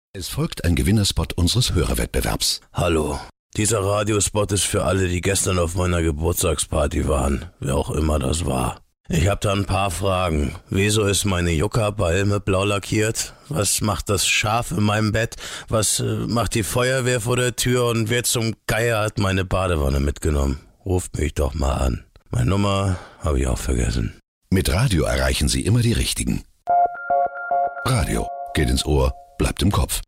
sehr variabel
Mittel plus (35-65)